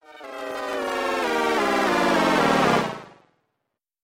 Звук поражения game over